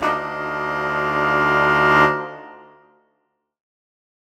Index of /musicradar/undercover-samples/Horn Swells/D
UC_HornSwell_Dmajminb6.wav